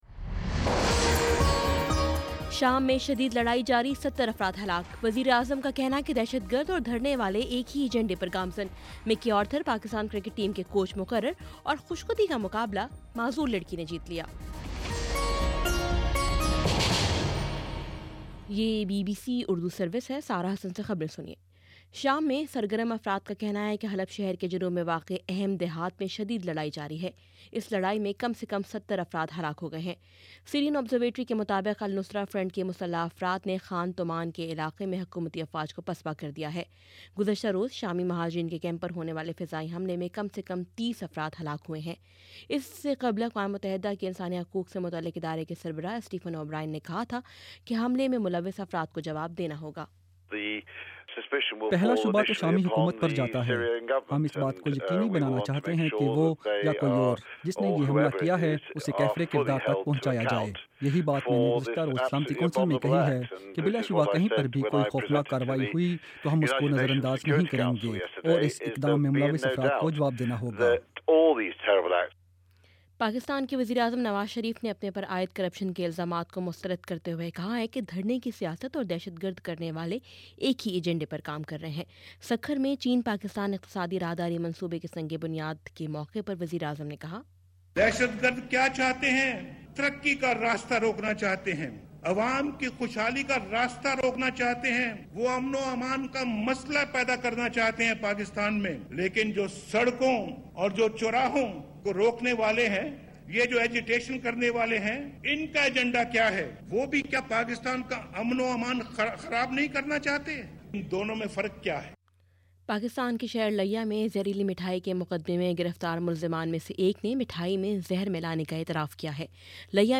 مئی 06 : شام چھ بجے کا نیوز بُلیٹن